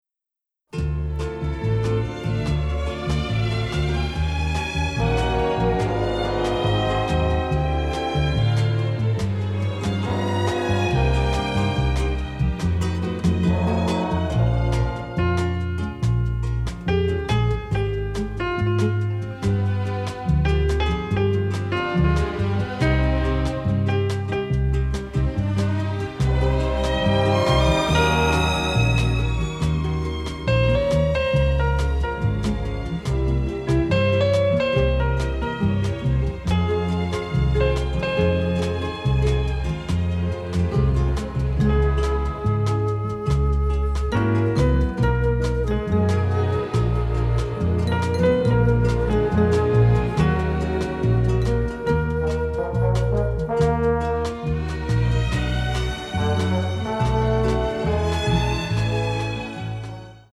catchy, lovely score